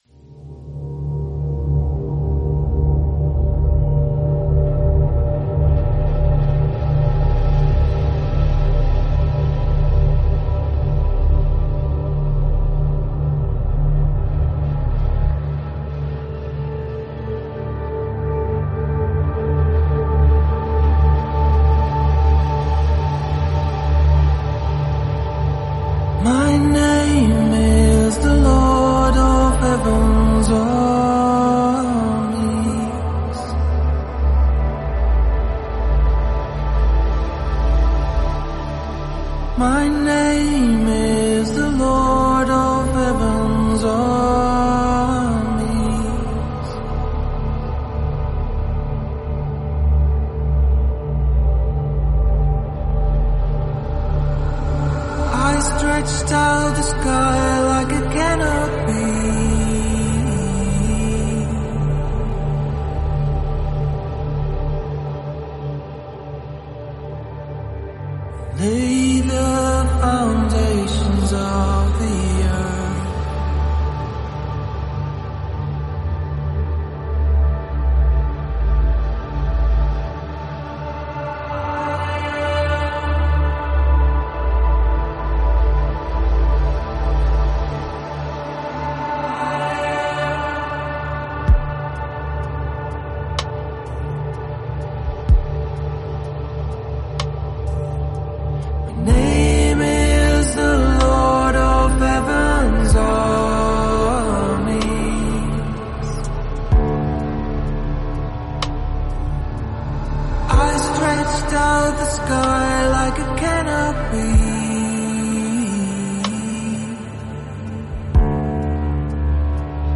Hear the Words of the Father sung to and over you.